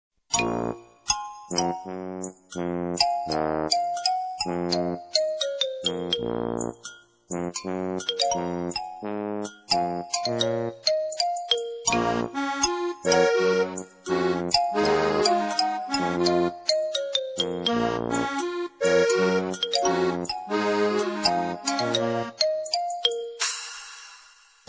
Extrait musical